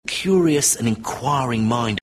Here is Prof. Jim Al-Khalili saying curious and inquiring mind, with smoothing of inqu/ɑjə/ring to inqu/ɑː/ring: